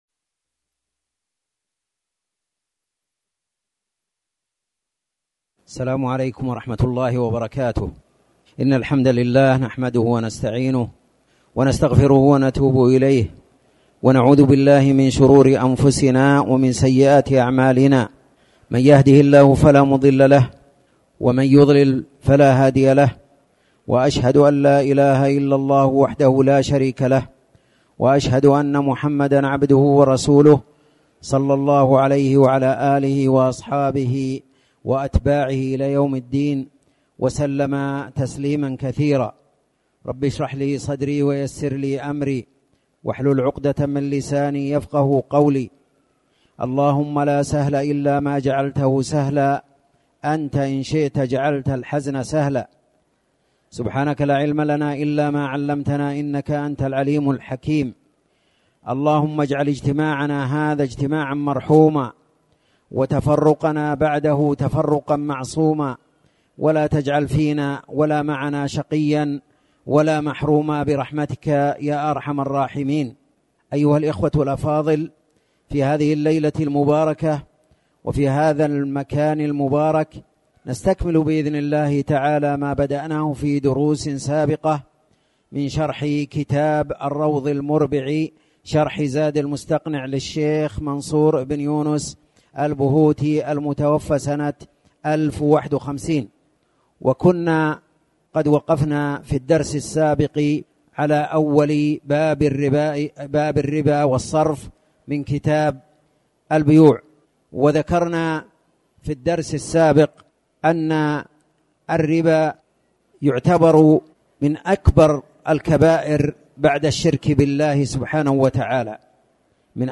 تاريخ النشر ١١ ربيع الثاني ١٤٤٠ هـ المكان: المسجد الحرام الشيخ